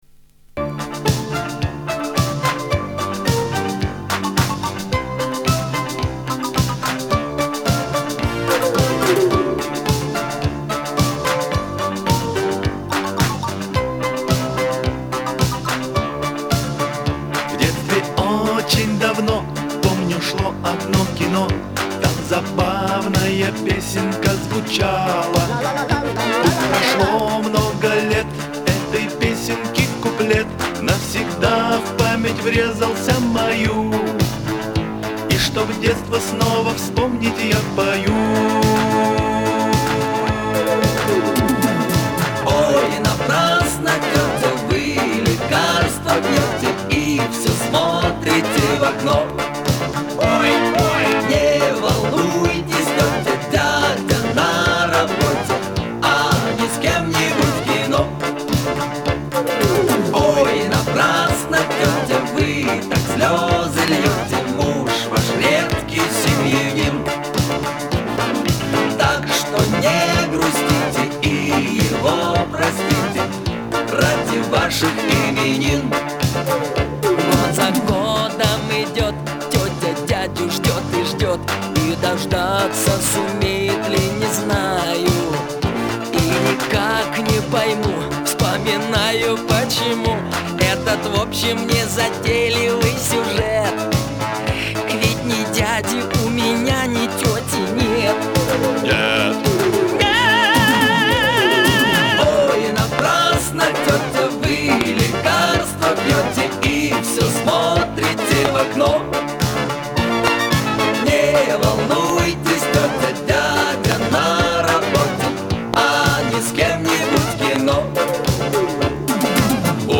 это зажигательная песня в жанре советского попа